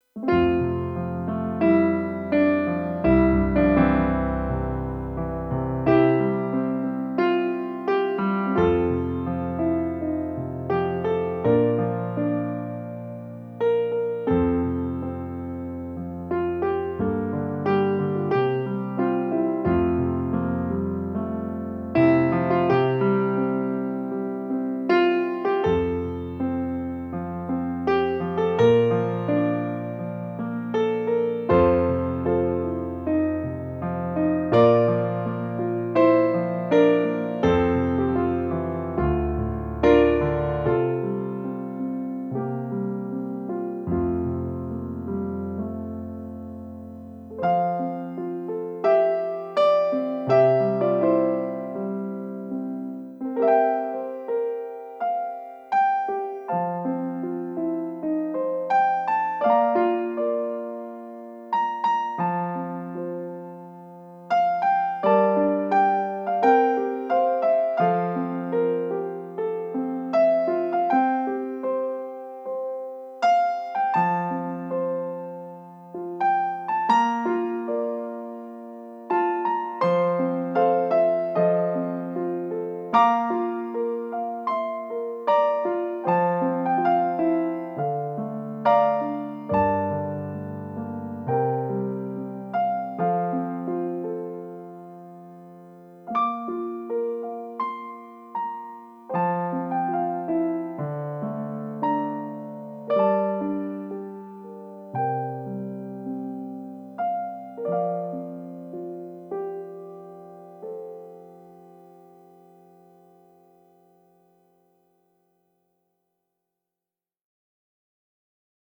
2024 Patriot Day Ceremony
17-God-Bless-America-Instrumental.m4a